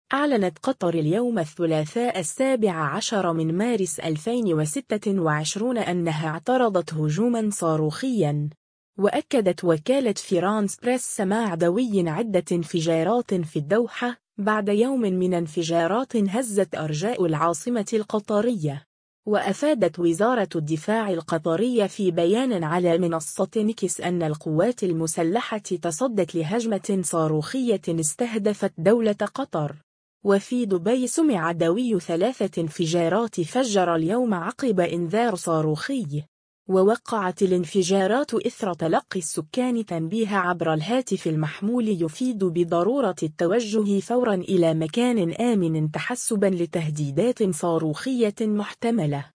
دويّ عدة انفجارات في الدوحة ودبي